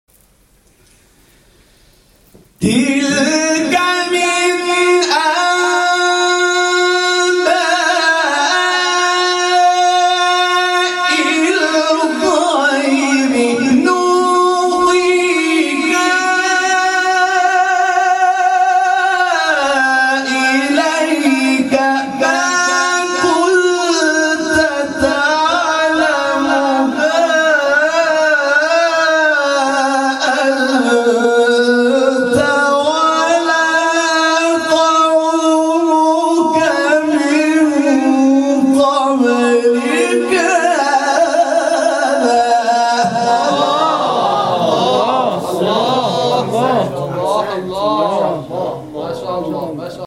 گروه شبکه اجتماعی: مقاطعی از تلاوت قاریان ممتاز و بین‌المللی کشور که به‌تازگی در شبکه اجتماعی تلگرام منتشر شده است، می‌شنوید.